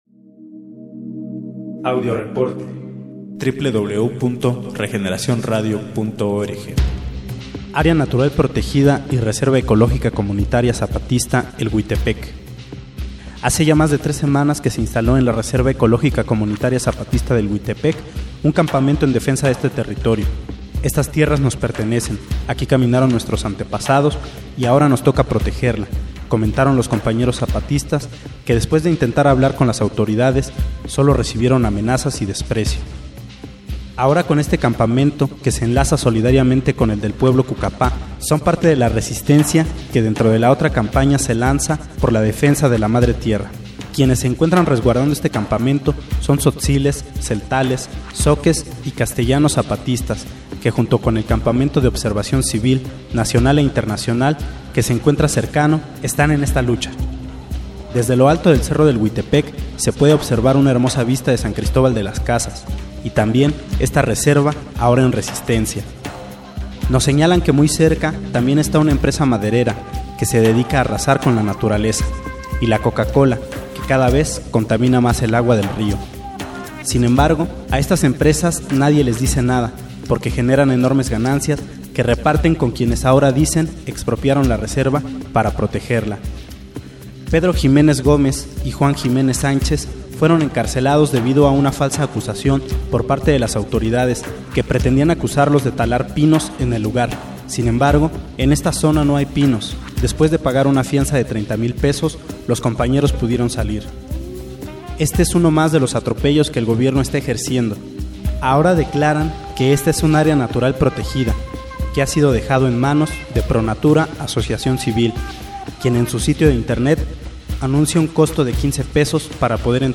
Reporte desde el Campamento Cucapá en Baja California